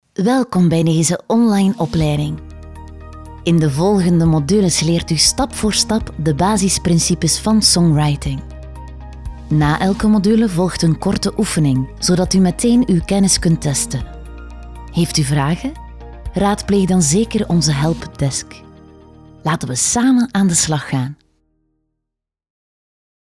Natürlich, Warm, Sanft, Zugänglich, Freundlich
E-learning